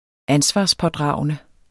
Udtale [ ˈansvɑsˌpʌˌdʁɑˀwənə ]